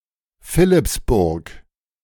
Philippsburg (German pronunciation: [ˈfɪlɪpsbʊʁk]
De-Philippsburg.ogg.mp3